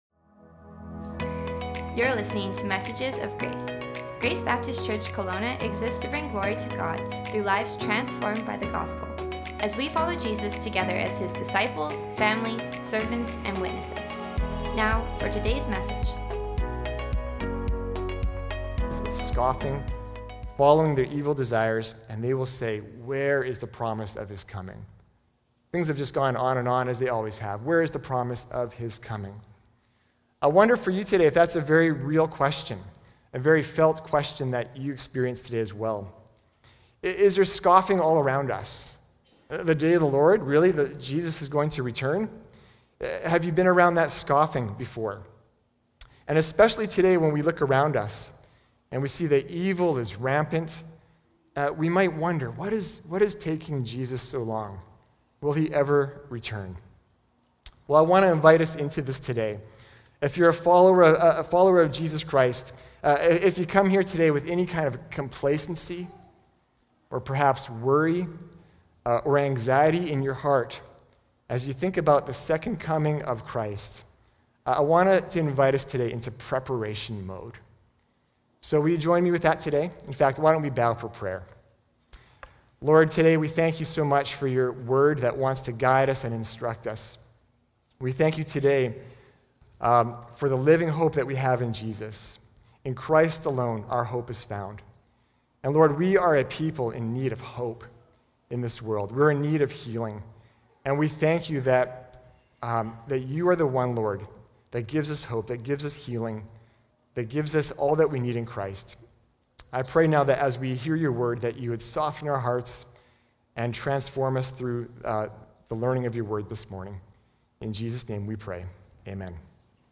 Sermons | Grace Baptist Church